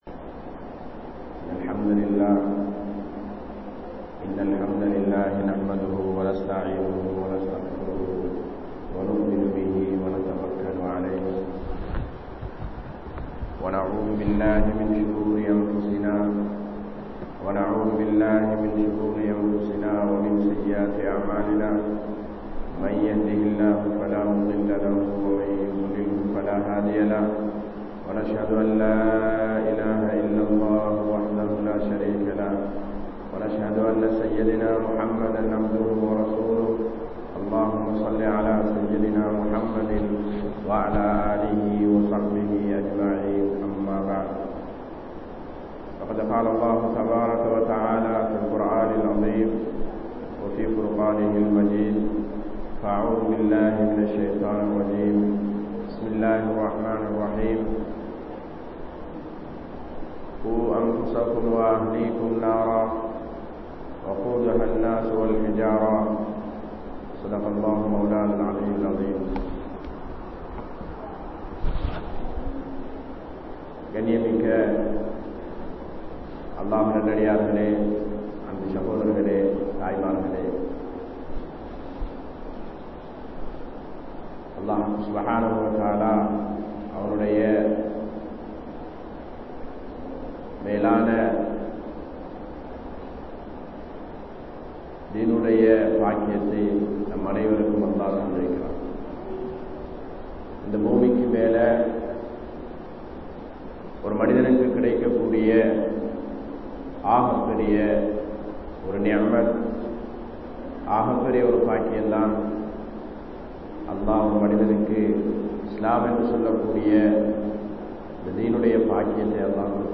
Petroarhal Pillaihalukku Seiya Maranthavaihal (பெற்றோர்கள் பிள்ளைகளுக்கு செய்ய மறந்தவைகள்) | Audio Bayans | All Ceylon Muslim Youth Community | Addalaichenai
Masjithur Ravaha